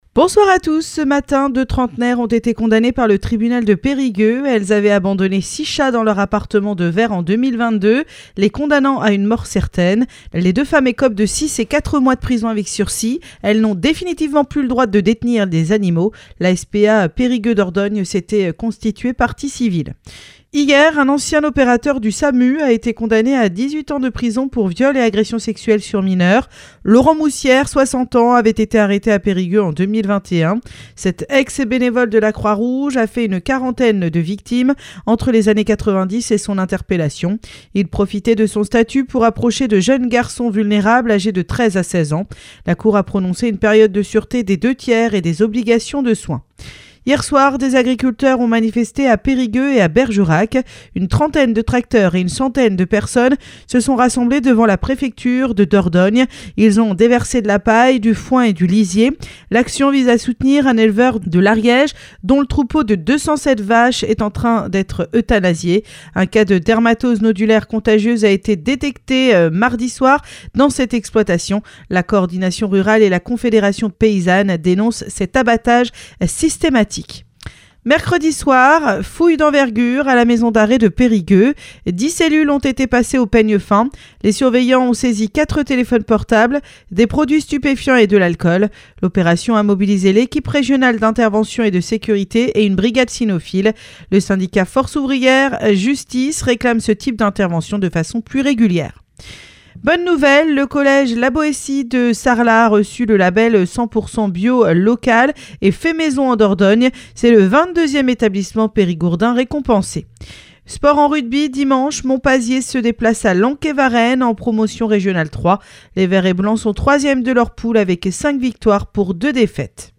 Happy Radio : Réécoutez les flash infos et les différentes rubriques